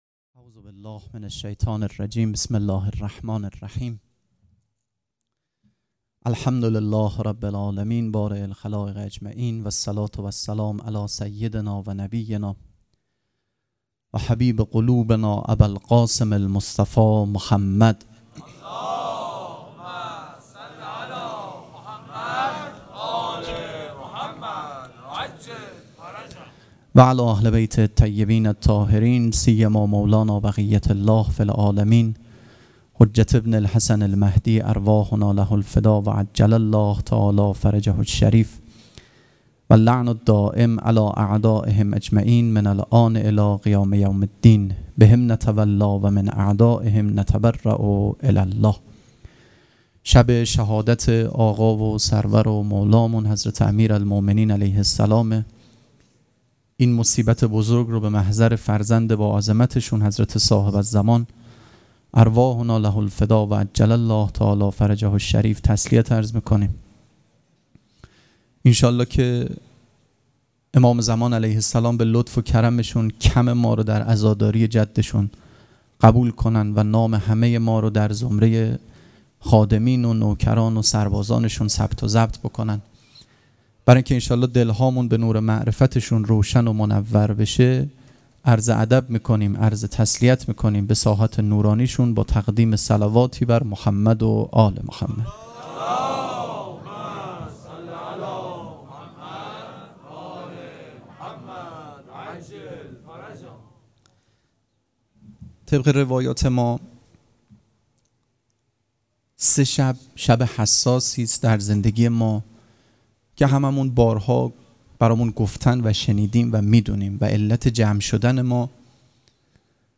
سخنرانی
مراسم مناجات شب بیست و یکم ماه رمضان دوشنبه ۱۳ اردیبهشت ۱۴۰۰ حسینیه ریحانة‌الحسین(س)